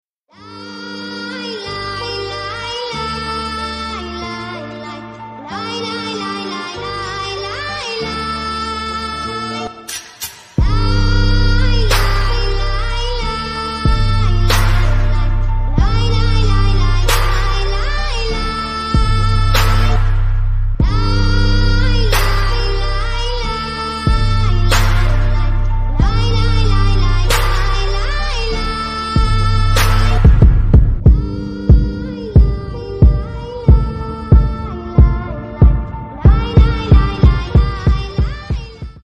• Качество: 128, Stereo
восточные мотивы
атмосферные
Trap
красивый женский голос
Стиль: trap